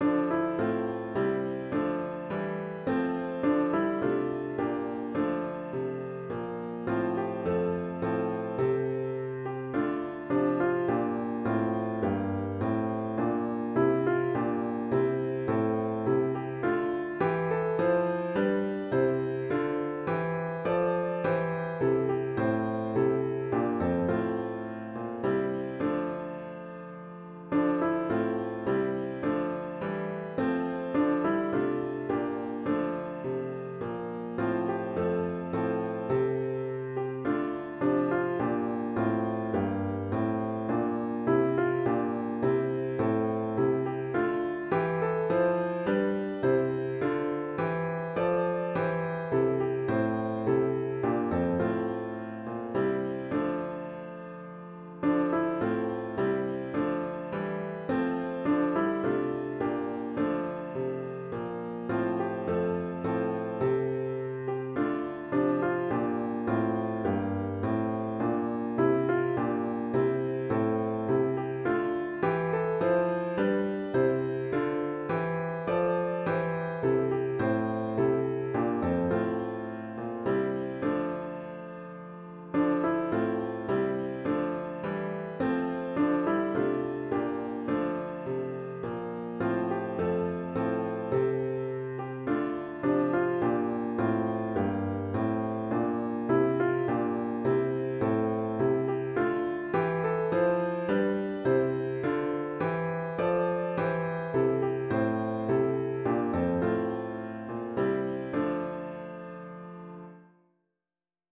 HYMN   “At the Name of Jesus”   GtG 264